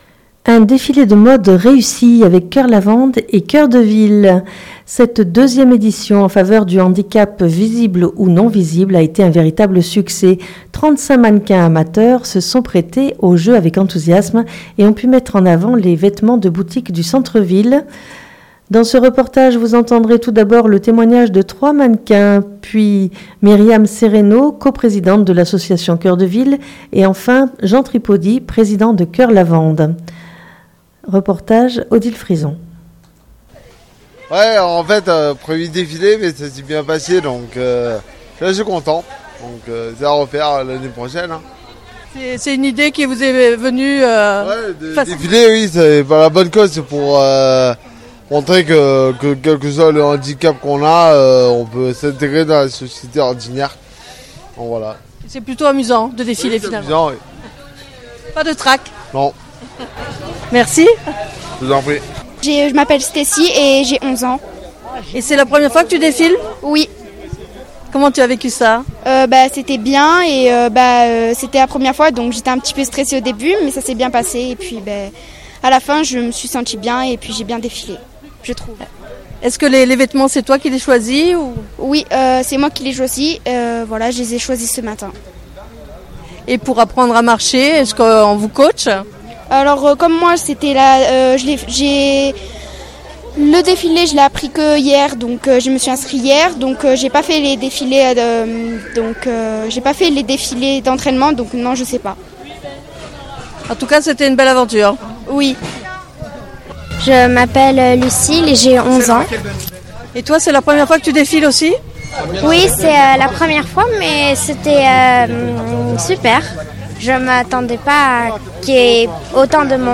Un défilé qui permet à la fois une visibilité et un coup de pouce aux commerces locaux tout en gardant des valeurs telles que celle d'unir les différences pour en faire une force. Dans ce reportage, vous entendrez tout d'abord le témoignage de 3 mannequins